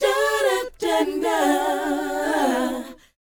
DOWOP A DU.wav